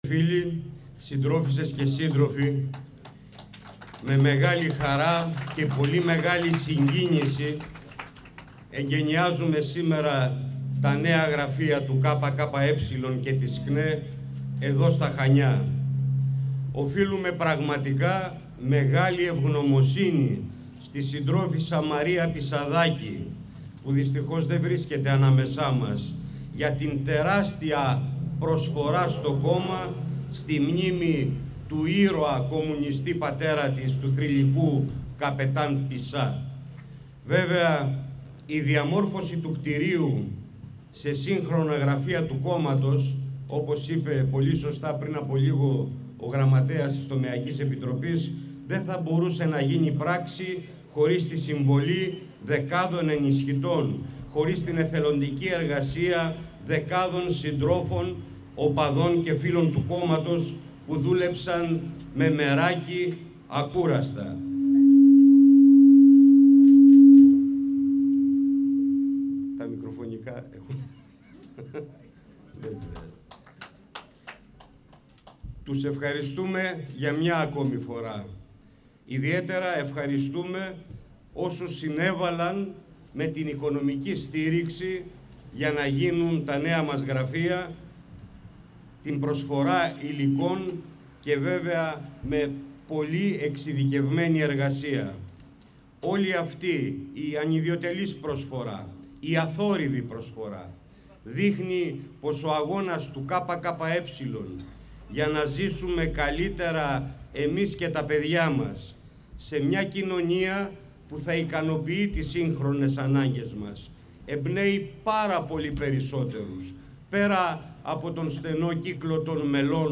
Παρουσία του Γεν. Γραμματέα του ΚΚΕ, πραγματοποιήθηκαν το απόγευμα του Σαββάτου, τα εγκαίνια των νέων γραφείων του κόμματος, στη Λεωφόρο Καραμανλή 8, στα Χανιά.
Η ομιλία του Γ.Γ του ΚΚΕ Δημήτρη Κουτσούμπα:
ΟΜΙΛΙΑ-ΕΓΚΑΙΝΙΑ-ΚΟΥΤΣΟΥΜΠΑΣ-ΗΧΗΤΙΚΟ.mp3